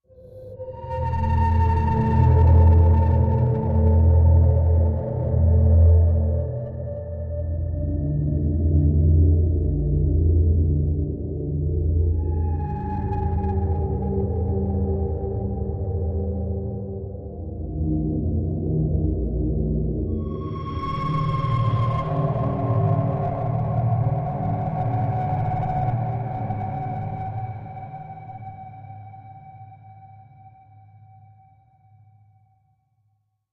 Ice Song High Metallic Voice Sweeps Over Moving Wind Drone